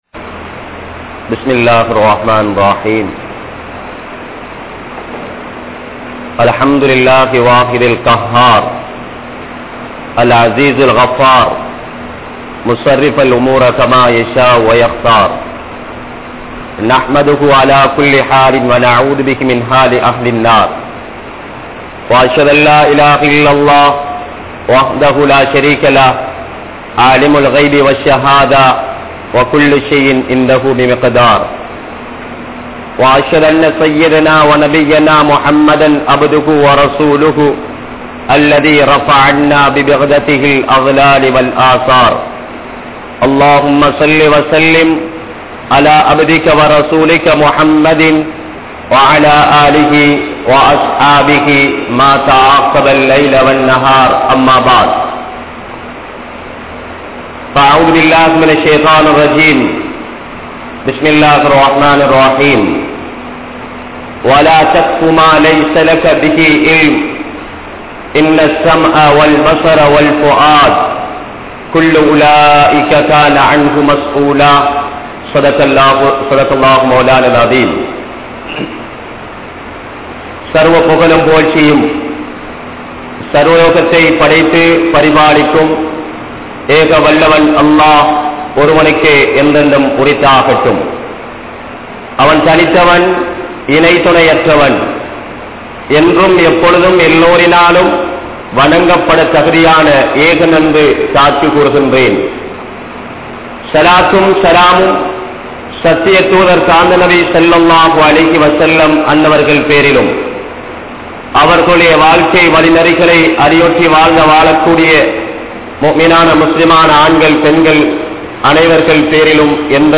Unarvuhalai Kattup Paduthugal (உணர்வுகளைக் கட்டுப்படுத்துங்கள்) | Audio Bayans | All Ceylon Muslim Youth Community | Addalaichenai
South Eastern University Jumua Masjith